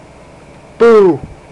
Boo! Sound Effect
Download a high-quality boo! sound effect.
boo-1.mp3